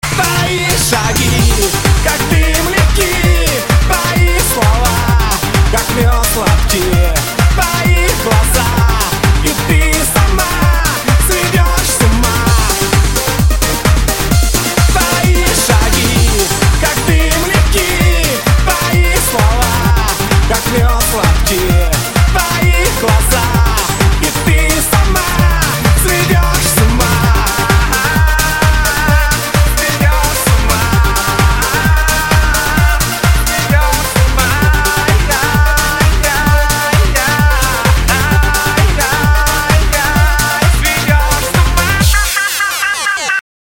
• Качество: 128, Stereo
remix